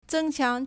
增强 (增強) zēngqiáng
zeng1qiang2.mp3